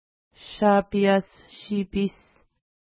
ID: 170 Longitude: -61.3637 Latitude: 55.0876 Pronunciation: ʃa:pia:ss-ʃi:pi:s Translation: Small Shapeiau River (small) Feature: river Explanation: Named in reference to lake Shapeiass (no 169) which it flows into.